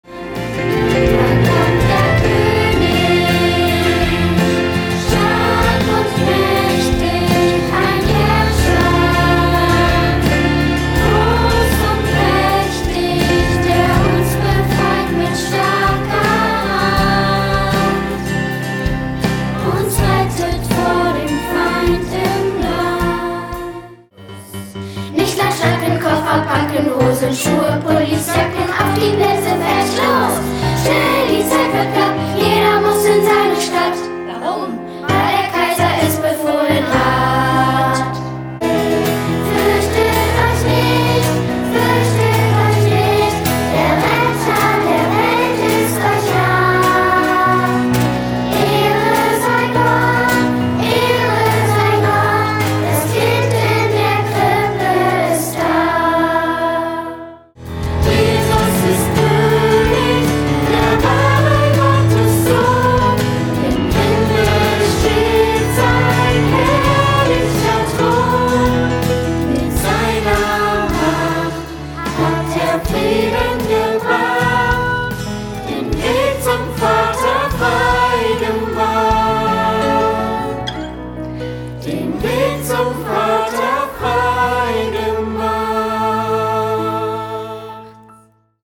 Kinderlied, Liedvortrag